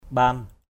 /ɓa:n/